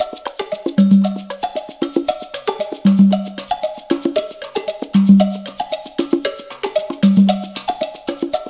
CONGA BEAT27.wav